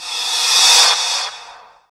43_08_revcymbal.wav